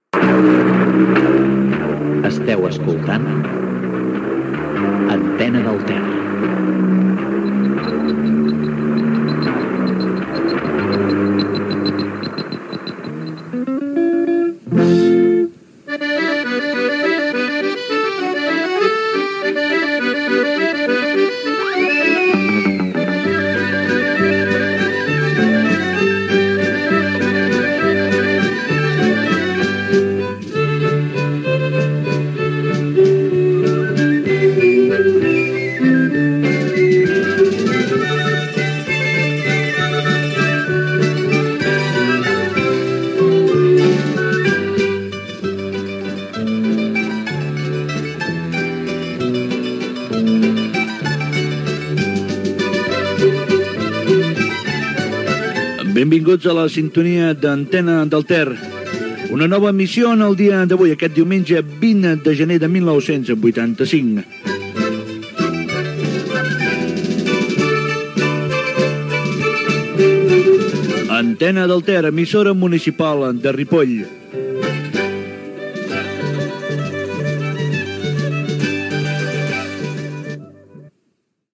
Identificació, inici de l'emissió amb la data i la presentació
Banda FM